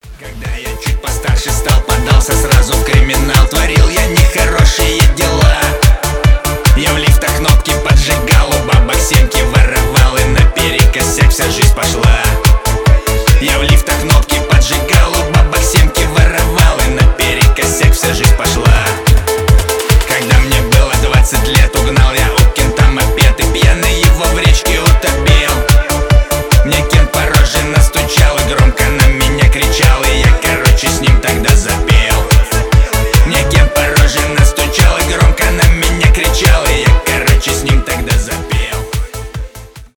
блатные
шансон